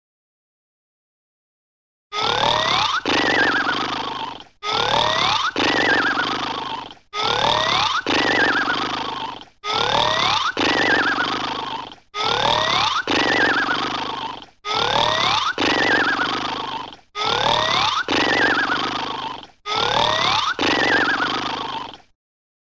Звуки мультяшного храпа
Комично похрапывает